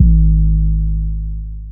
DY Overused 808.wav